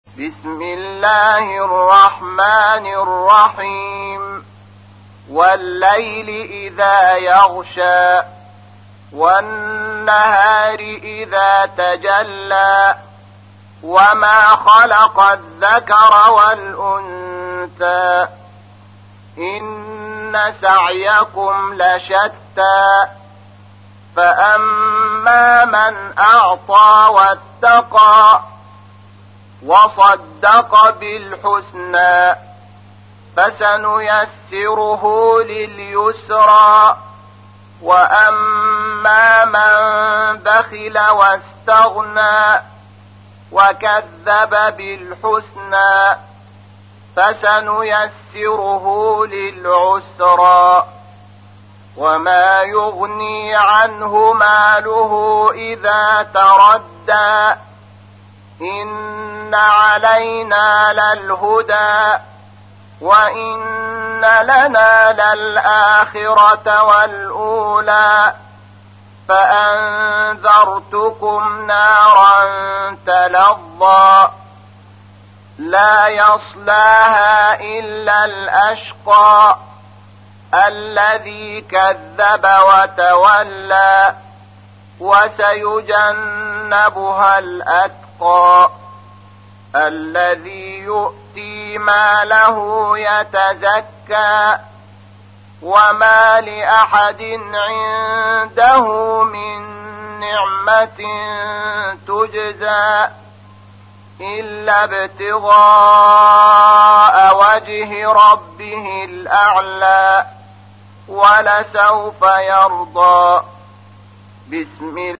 تحميل : 92. سورة الليل / القارئ شحات محمد انور / القرآن الكريم / موقع يا حسين